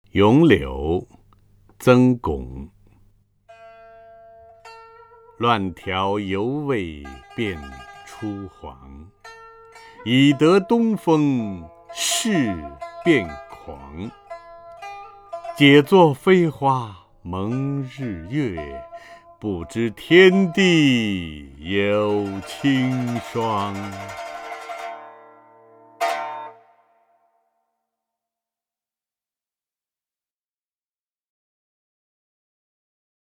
陈铎朗诵：《咏柳》(（北宋）曾巩) （北宋）曾巩 名家朗诵欣赏陈铎 语文PLUS
（北宋）曾巩 文选 （北宋）曾巩： 陈铎朗诵：《咏柳》(（北宋）曾巩) / 名家朗诵欣赏 陈铎